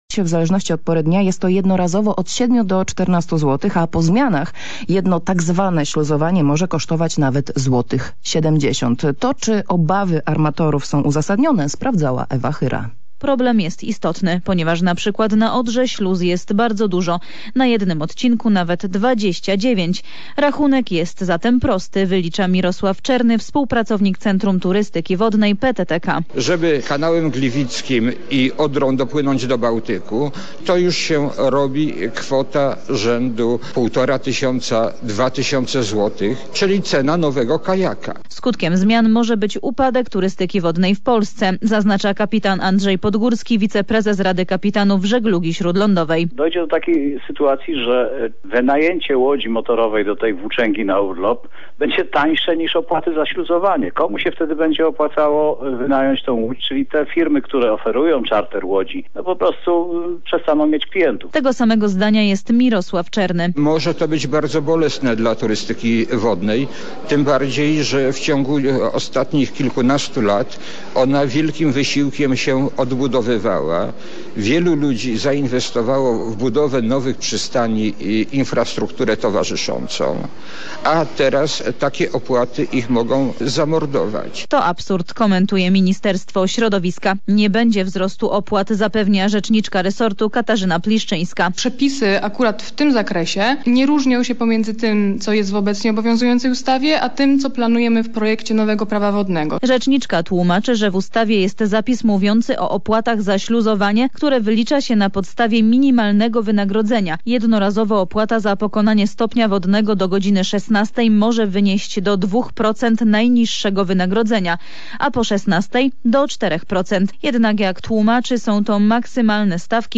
Moja wypowiedź odnośnie tych opłat zabrzmiała w Polskim Radio ale też tematem były opłaty dla turystów: